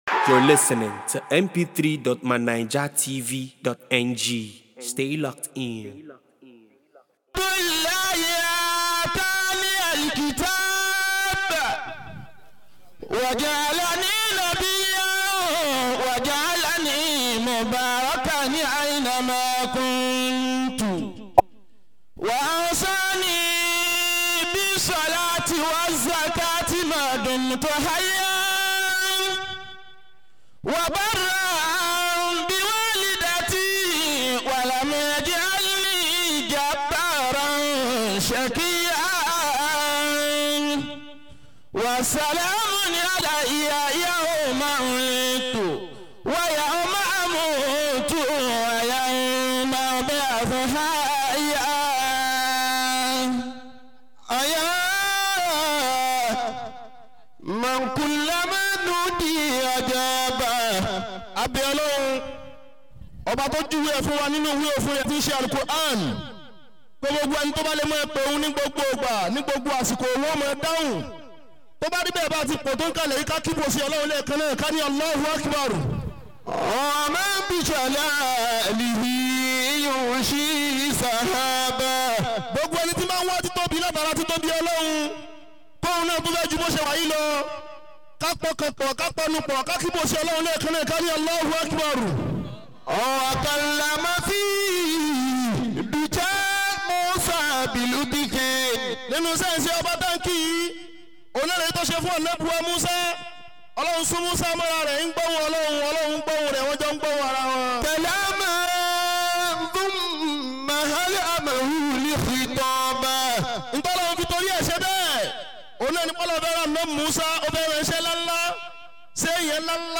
Walimatul Quran